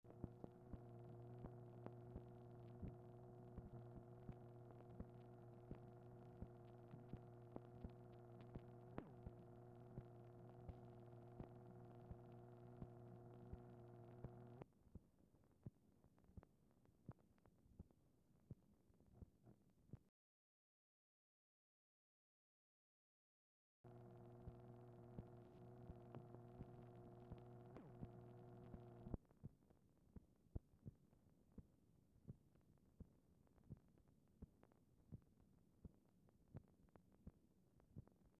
Telephone conversation # 5401, sound recording, MACHINE NOISE, 9/1/1964, time unknown | Discover LBJ
Telephone conversation
Format Dictation belt